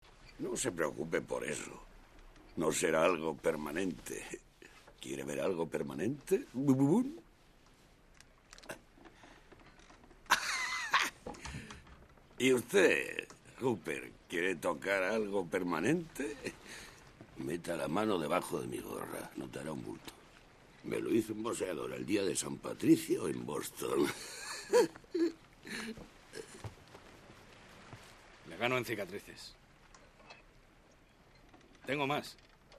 SONIDO (VERSIÓN DOBLADA)
redoblaje del 2003, el único incluido en la nueva edición en DVD del 30 aniversario.